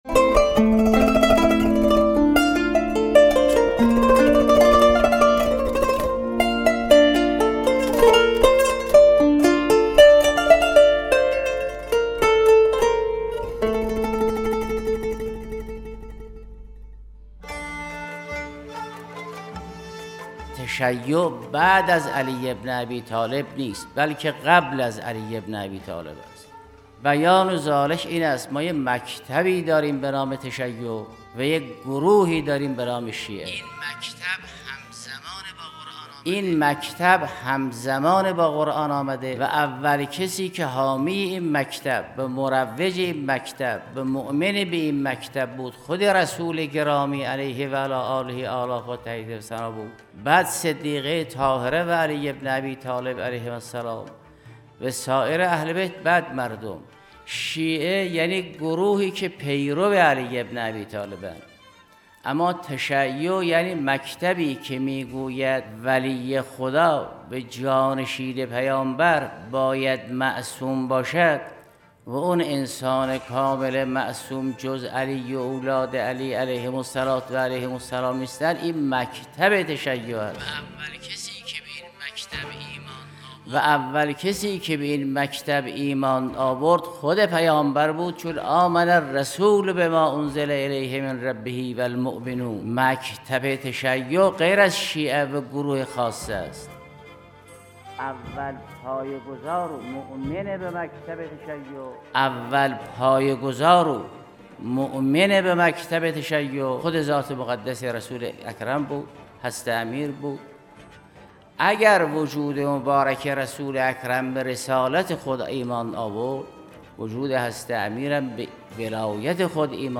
به گزارش پایگاه خبری 598، آیت الله جوادی آملی در یکی از دروس اخلاق به موضوع «جایگاه مکتب تشیع در تاریخ اسلام» پرداختند که تقدیم شما فرهیختگان می شود.